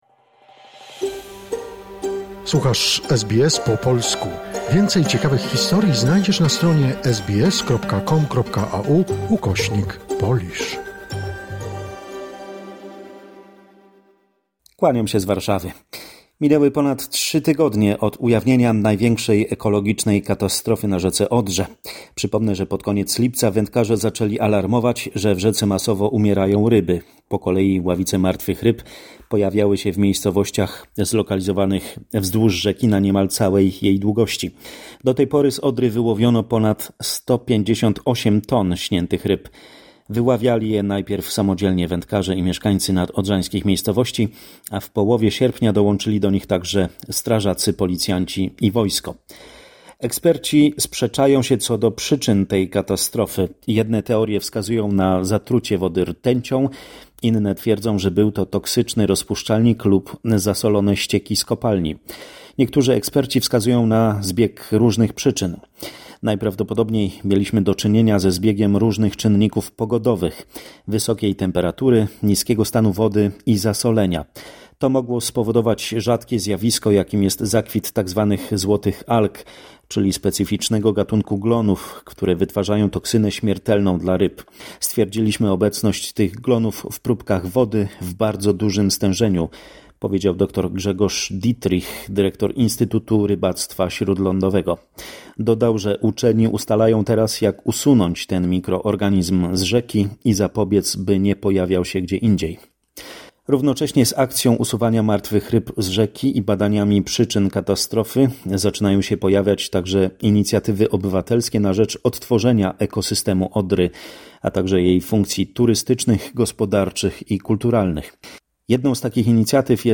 Summary of the important events in Poland. Report